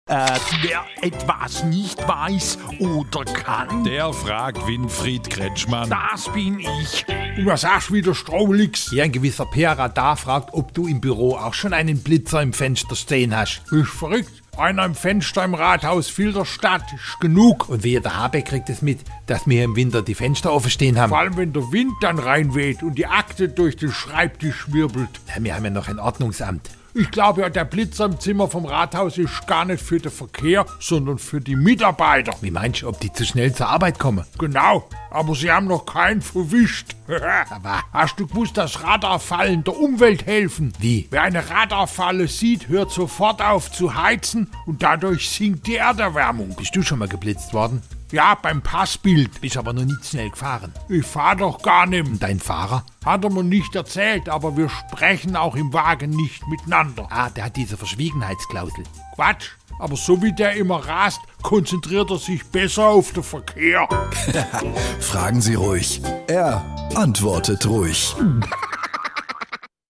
beim SWR3 Comedy Festival 2024